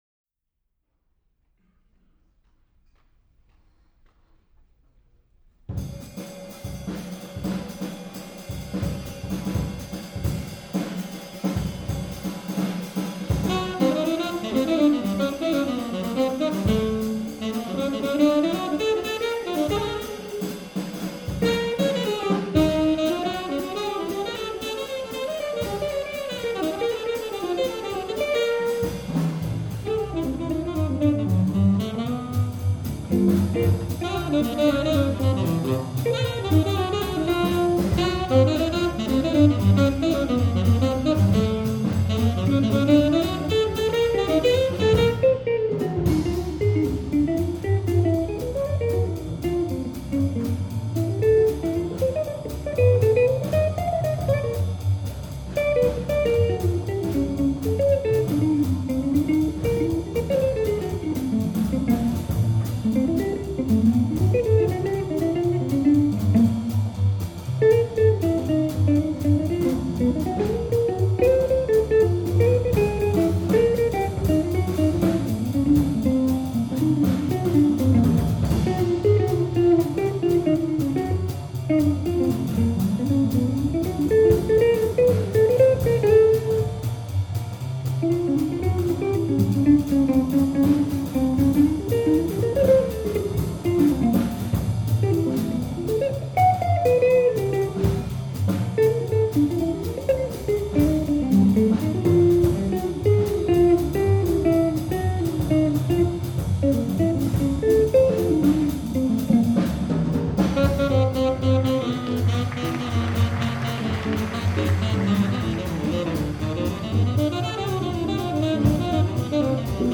Quartet
guitar
tenor saxophone
bass
drums